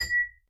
glass D
class cup ding dink effect sound tap tapping sound effect free sound royalty free Sound Effects